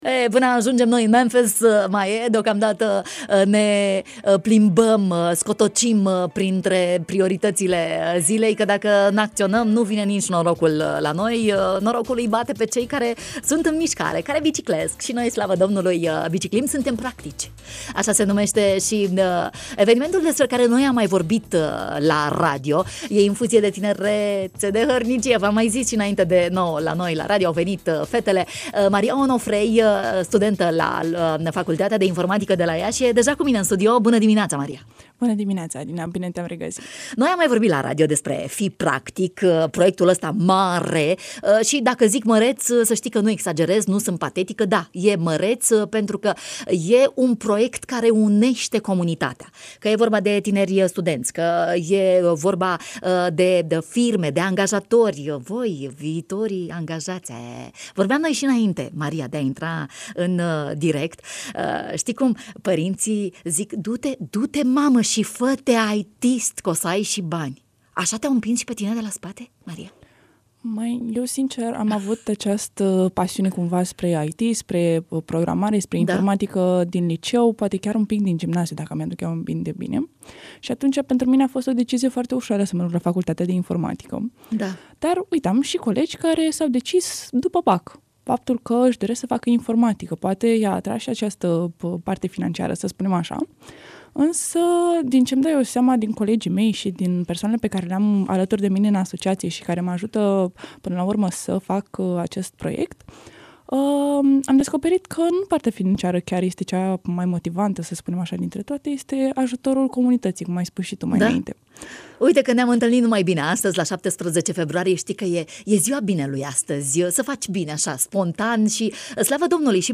În studio, în direct la matinalul de la Radio România Iași